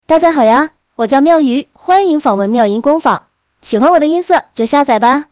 自己训练的大姐姐模型，偏向于东北豪爽大姐姐，说话可以稍微豪放一点。
原始音色